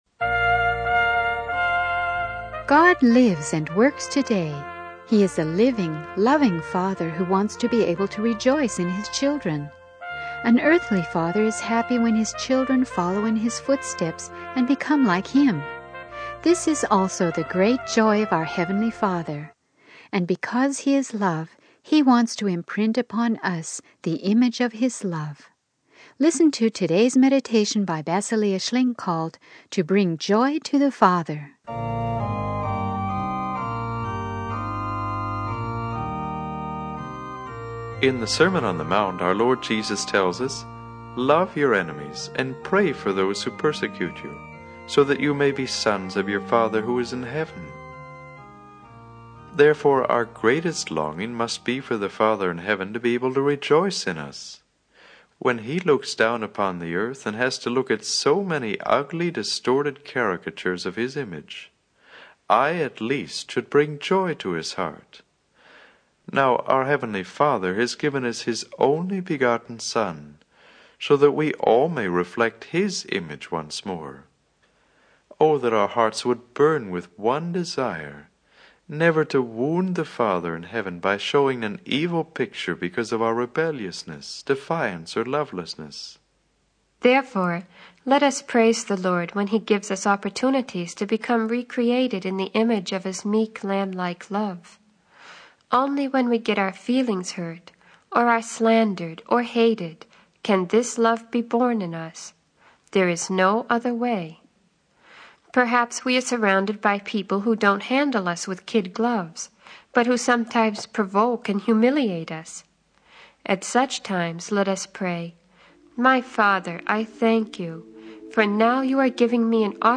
The sermon emphasizes the importance of reflecting God's image and radiating Jesus' likeness in order to bring joy to the Father.